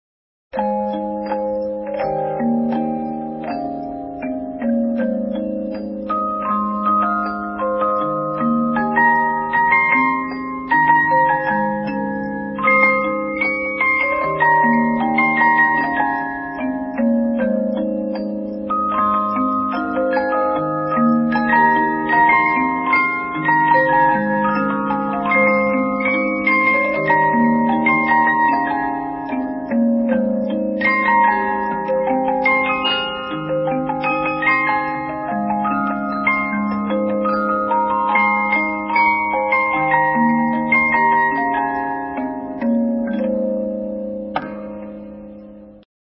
Music box Sound Bites of 50 note movements